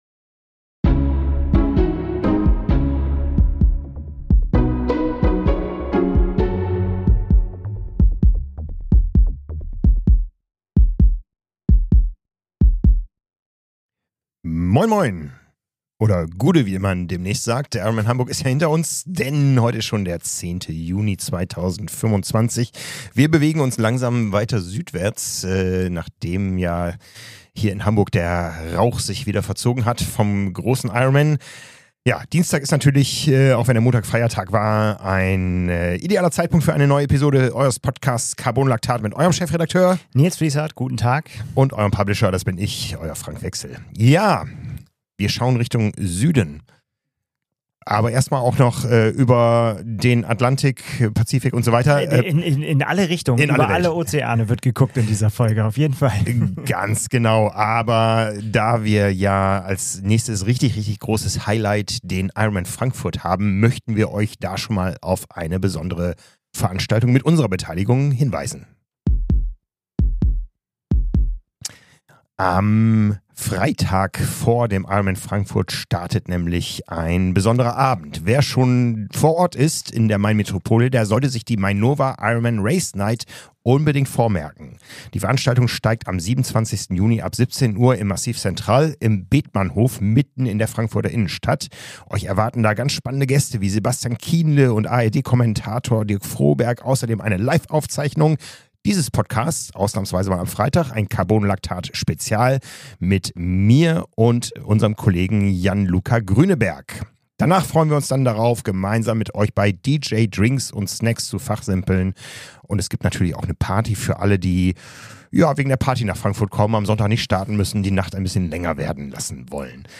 Zwei Experten aus der Redaktion sprechen über das aktuelle Triathlongeschehen.